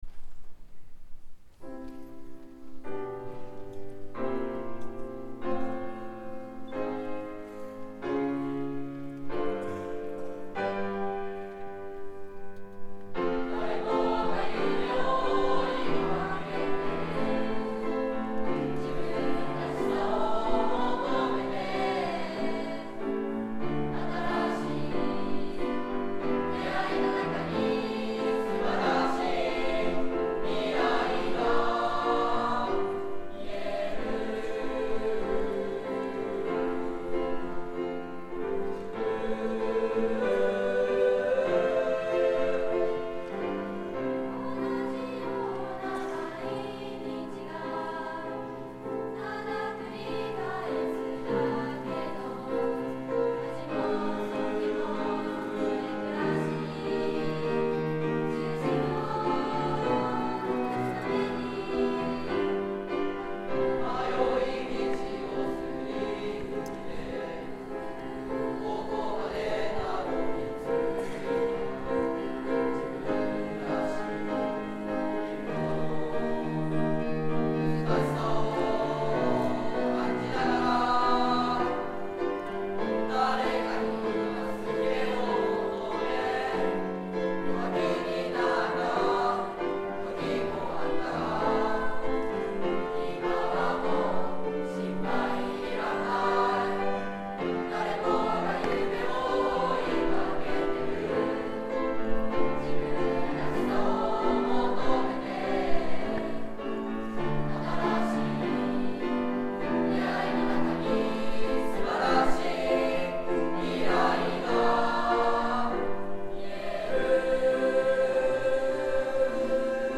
２Ａ 自分らしく.mp3 ←クリックすると合唱が聴けます